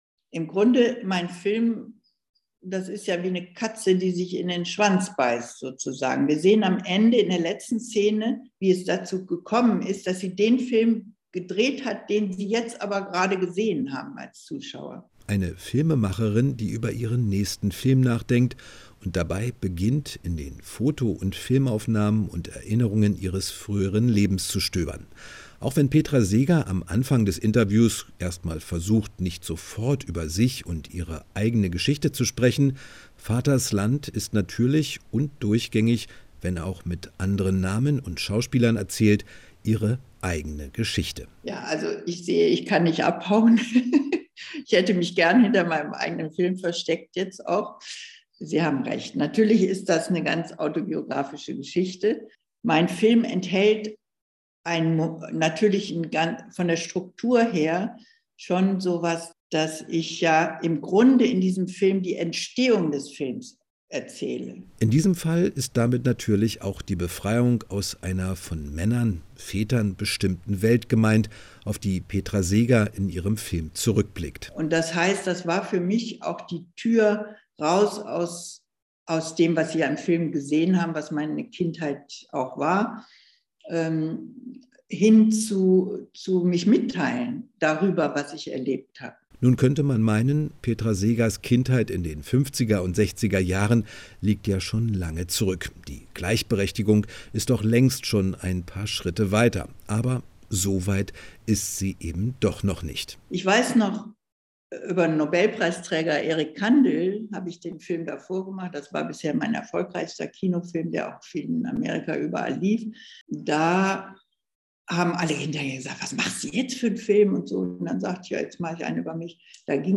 Kinogespräch